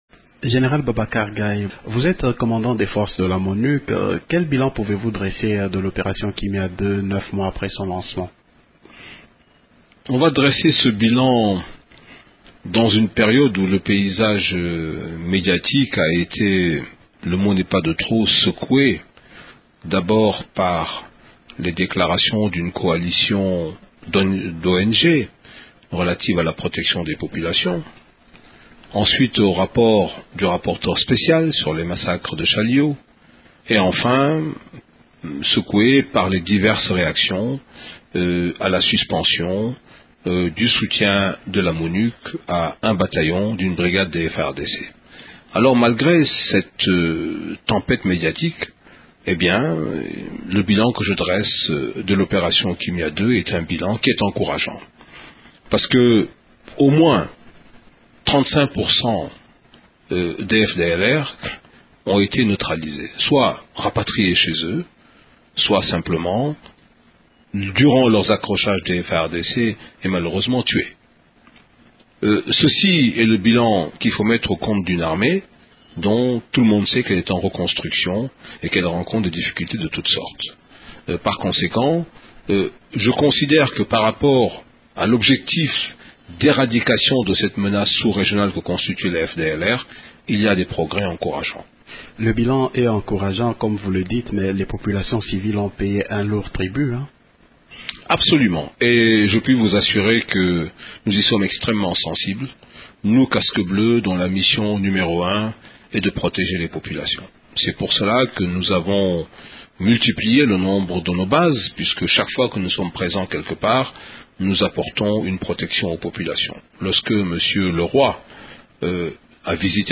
Nous en parlons avec notre invité du jour, le Général Babacar Gaye, Commandant des forces de la Monuc.